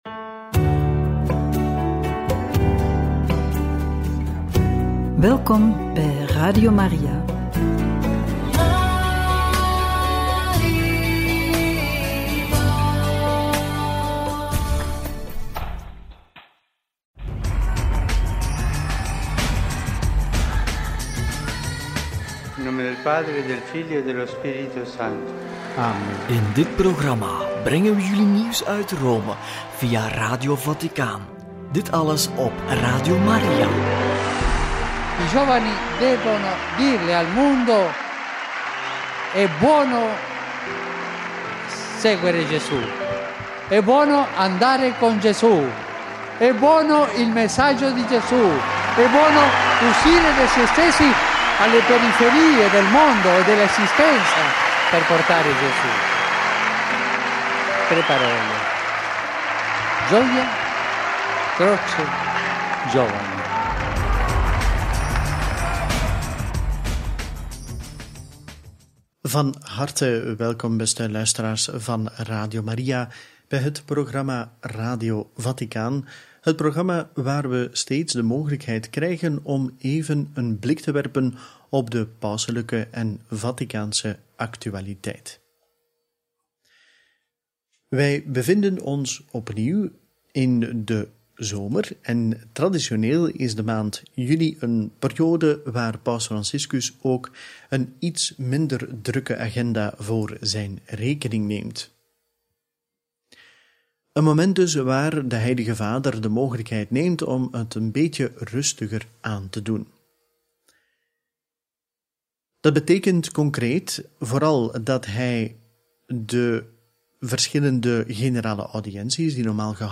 Mgr. Carlo Maria Vigano geëxcommuniceerd – Paus Franciscus bezoekt Trieste voor de 50e ‘sociale week’ – Interview met kardinaal Hollerich over de synode – Radio Maria